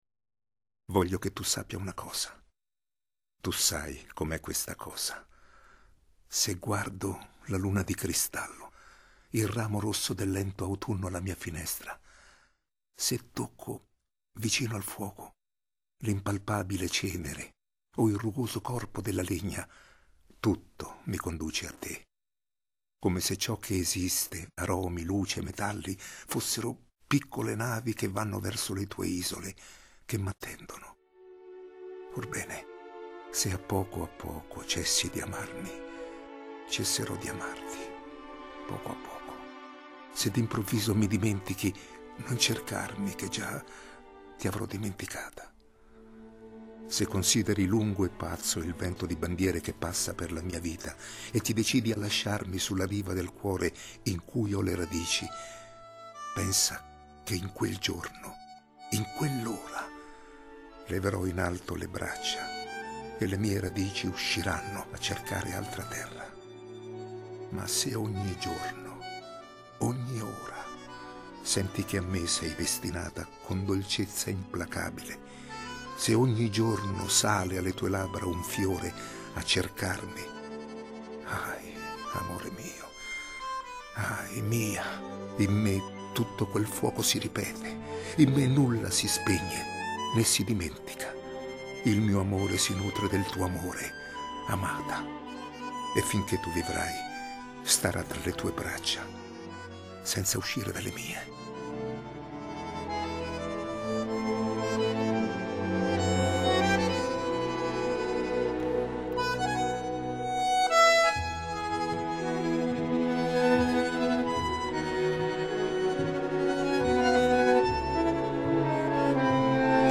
(legge Ferruccio Amendola)